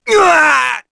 Neraxis-Vox_Damage_kr_03.wav